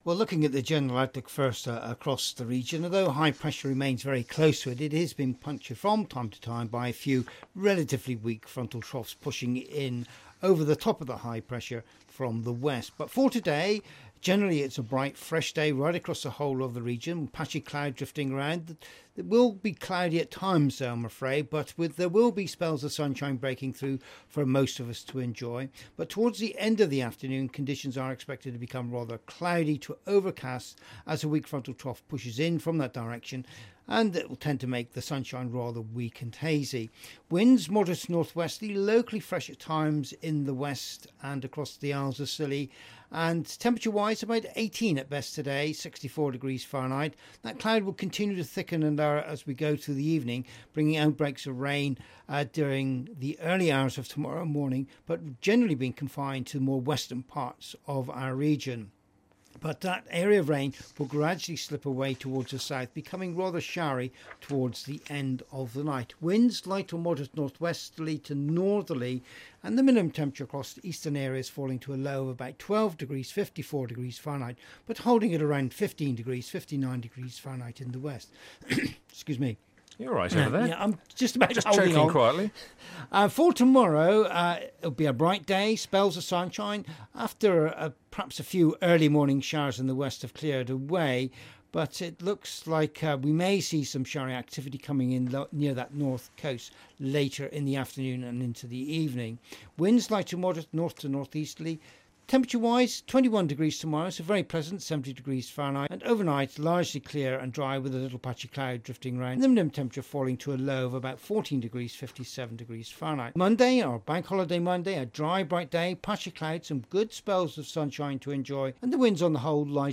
5 Day weather forecast for Cornwall and the Isle of Scilly